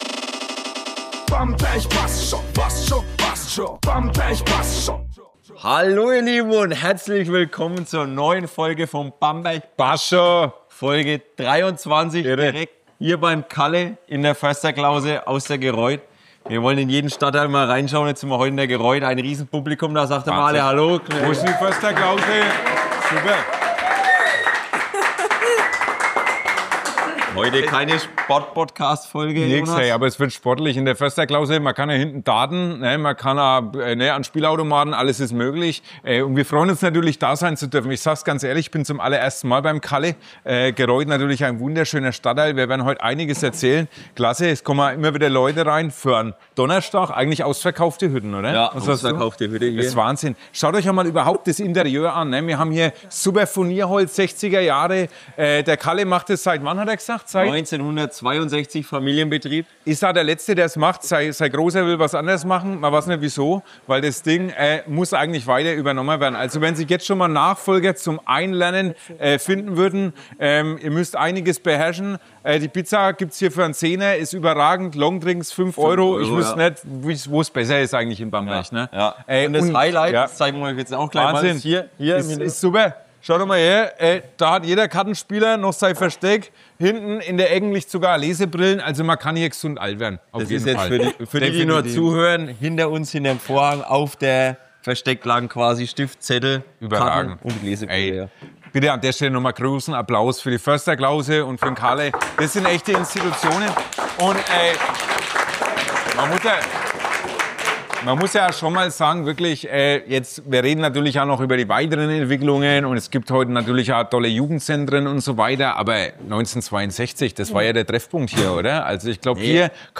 Ein echter Stammtischabend mit Herz, Humor und Geschichte – mitten aus der Gereuth.